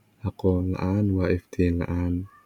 Somali-tts / tts_somali_finetuned like 0 Follow Somali-tts 9